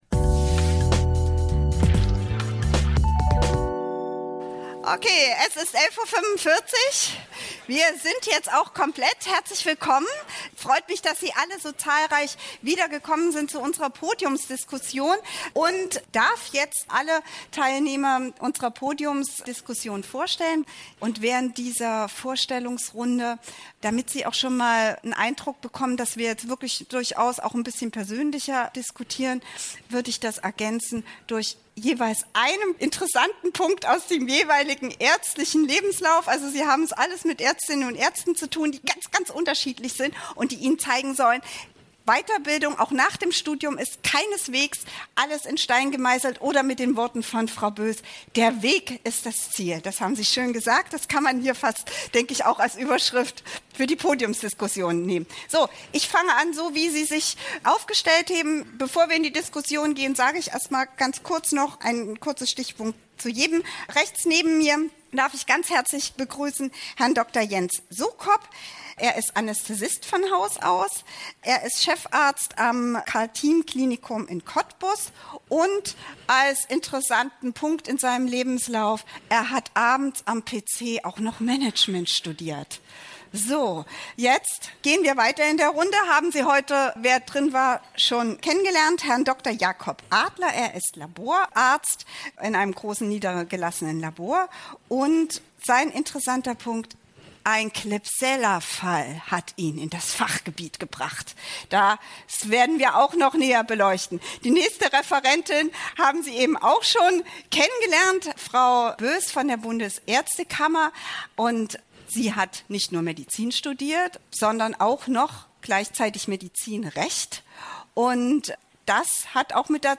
Warum haben sich erfahrene Ärztinnen und Ärzte für eine bestimmte Fachrichtung entschieden? Bei der Podiumsdiskussion auf dem Operation Karriere-Kongress in Berlin am 3. Dezember 2022 ging es um dieses Thema.